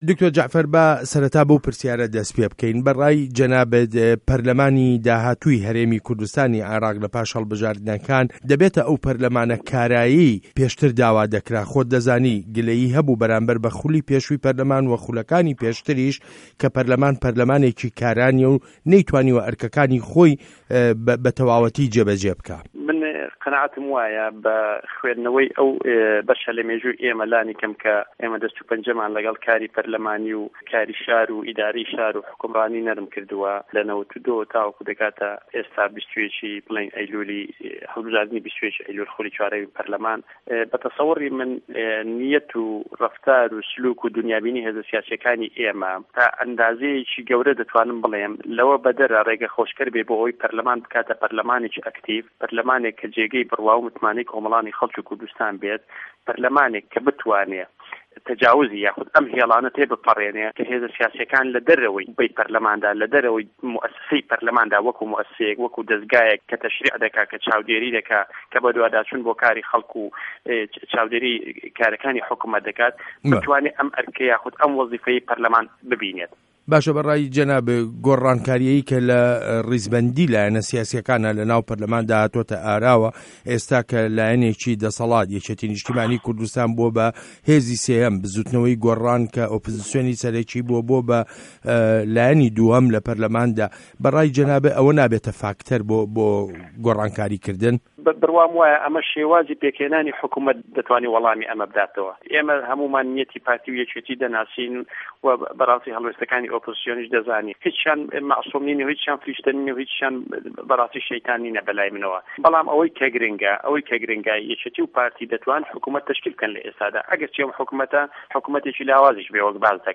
وتووێژ له‌گه‌ڵ دکتۆر جه‌عفه‌ر عه‌لی